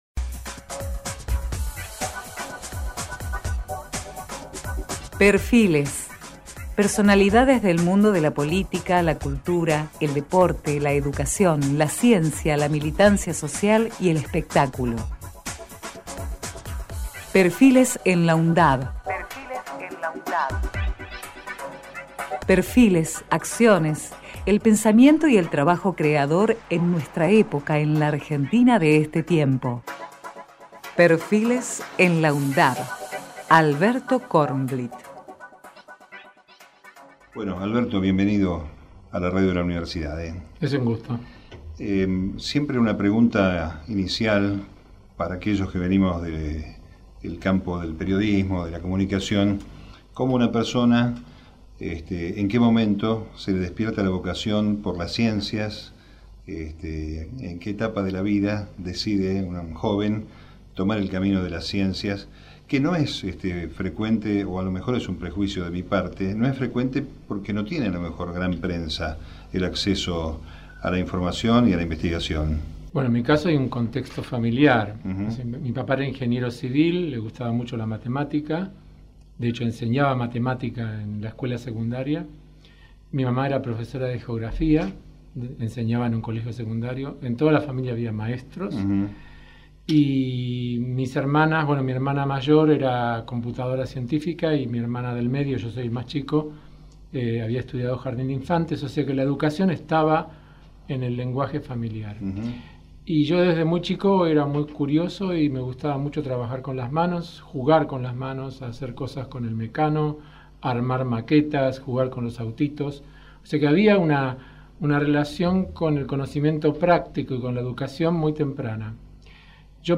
PERFILES ALBERTO KORNBLIHTT Texto de la nota: Perfiles en la UNDAV Entrevista realizada en 2017 al biólogo molecular del Conicet Alberto Kornblihtt.